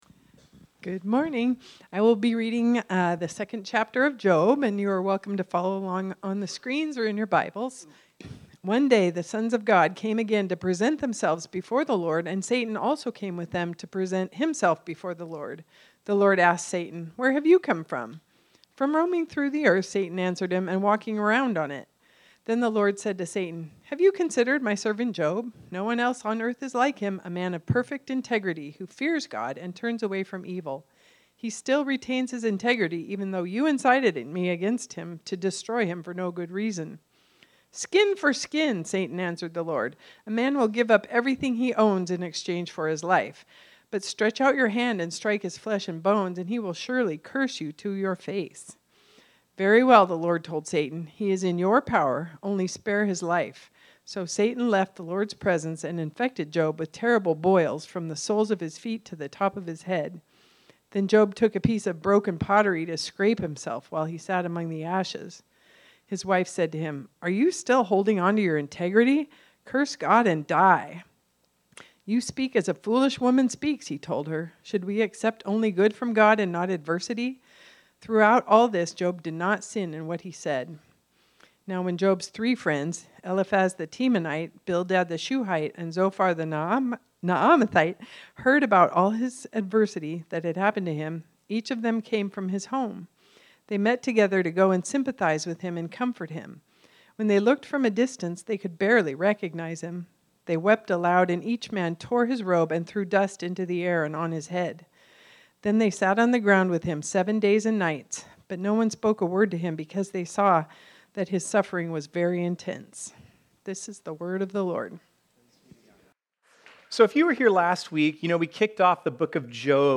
This sermon was originally preached on Sunday, January 11, 2026.